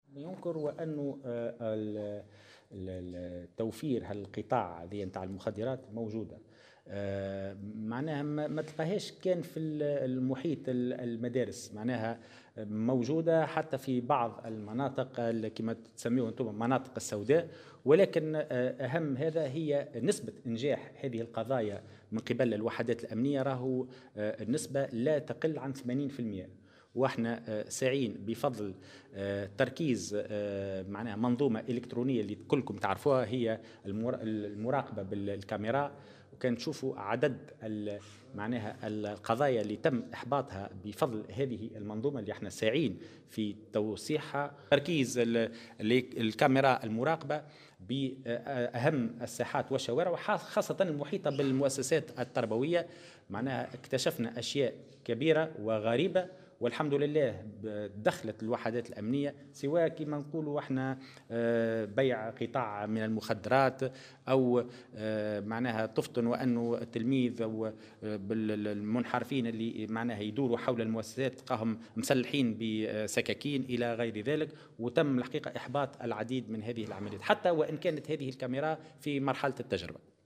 وأضاف في تصريح اليوم على هامش ورشة عمل للنظر في وضع استراتيجية للتصدي والحد من الظواهر السلبية التي تحيط بالمؤسسات التربوية، أن الوحدات الأمنية تدخلت على الفور وتمكنت من إحباط عمليات سطو "براكاج" أو عمليات بيع مخدرات.